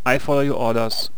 archer_ack2.wav